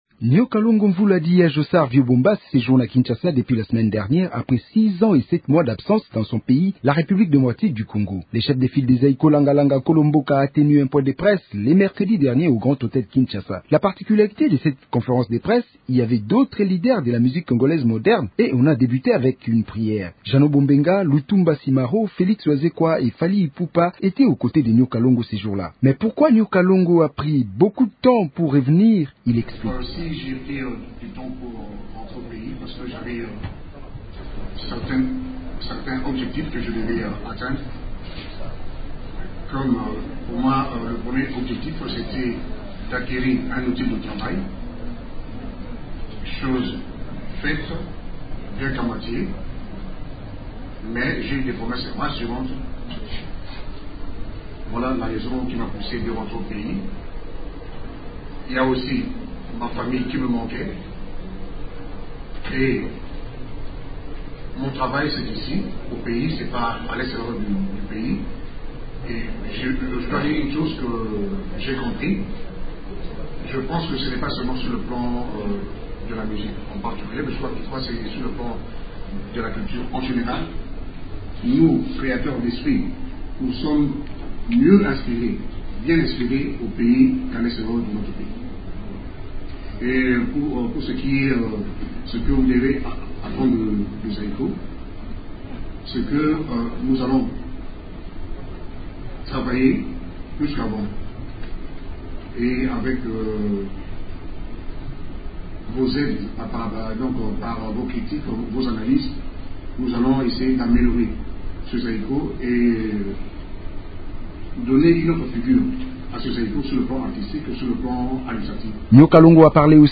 Nyoka Longo Mvula alias Ya Jossart ou vieux Bombas séjourne à Kinshasa dépuis le vendredi 27 février 2009, après 6 ans et 7 mois d’absence, dans son pays, la R.D.C. Le chef de file de l’orchestre Zaiko Langa Langa Nkolo Mboka a tenu un point de presse le mercredi 4 mars au Grand Hotel Kinshasa. Quelques leaders de la chanson congolaise,en l’occurencern Jeannot Bombenga, Lutumba Simaro, Félix Wazekwa et Fally Ipupa ont pris part à ce point de presse.